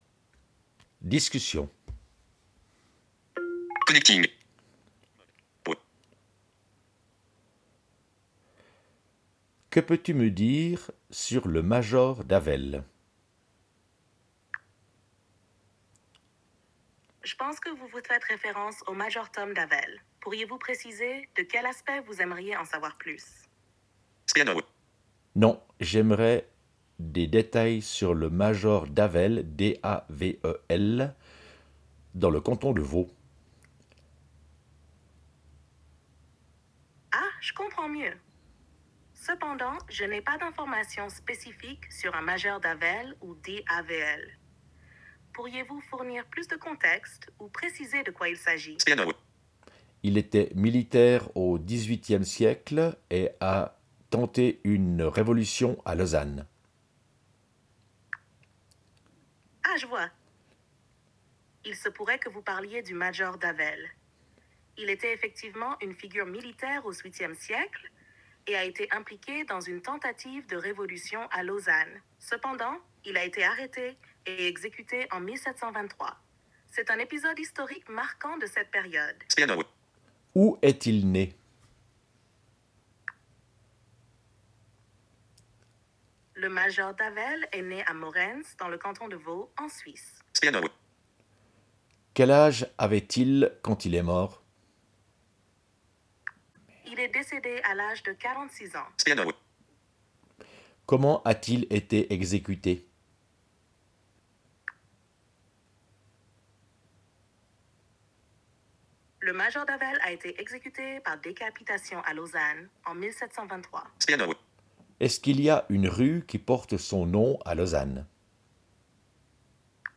Grâce au module ChatGPT Voice, tu peux causer avec le chatbot et obtenir des réponses orales.
dialogue-avec-chatgpt.mp3